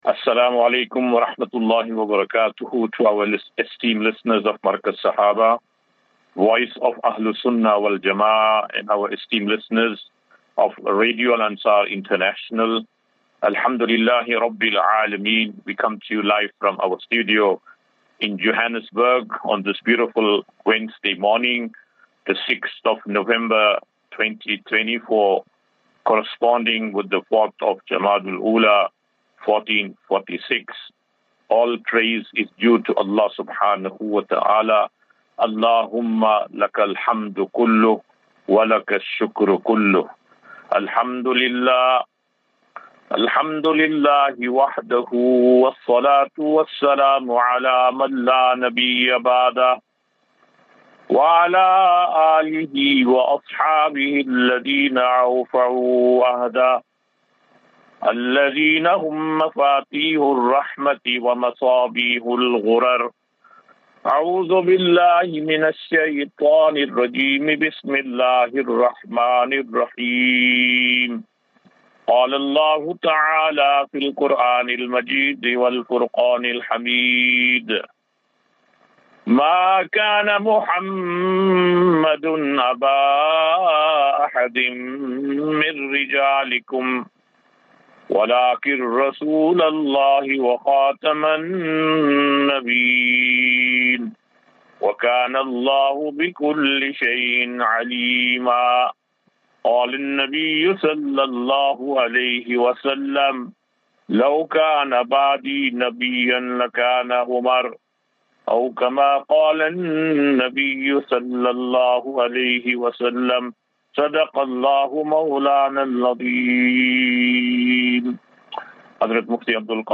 Assafinatu - Illal - Jannah. QnA
Daily Naseeha.